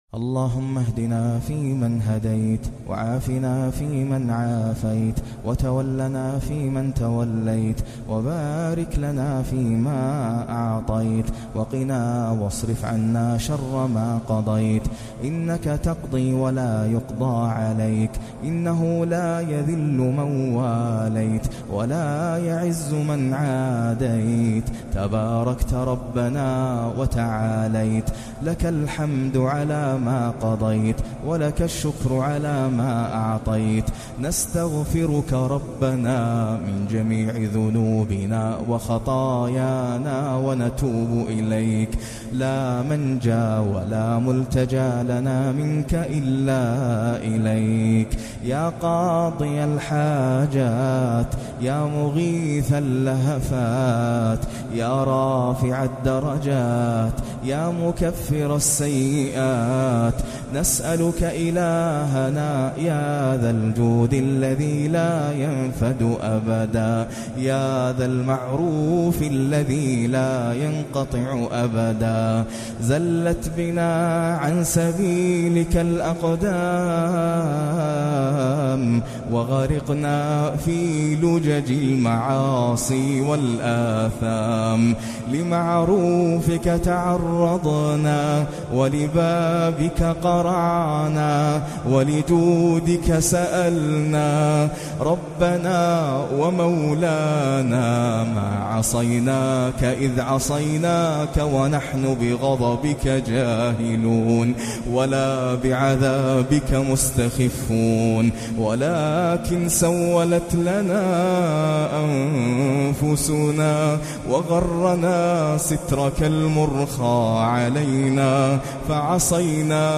اللهم لاتشوي خلقنا بالنار - قنوت الشيخ ناصر القطامي تهجد ليلة 25 رمضان 1439
أدعية ومناجاة